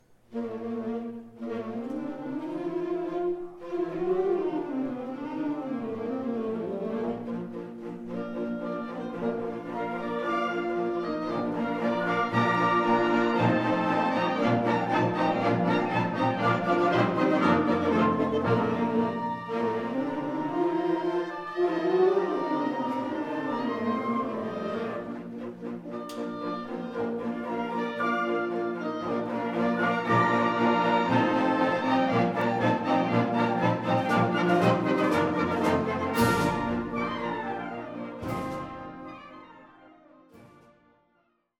Swift Creations specializes in live and on location recordings in Pennsylvania.
Concert Band 2
concert-band.mp3